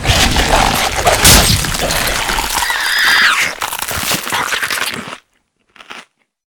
eat.ogg